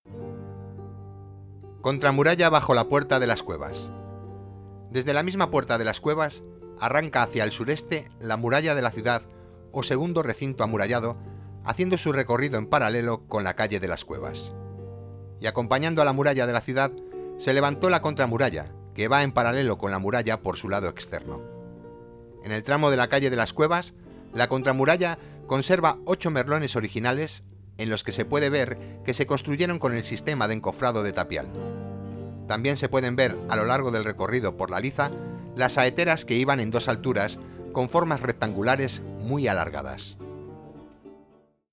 audio guías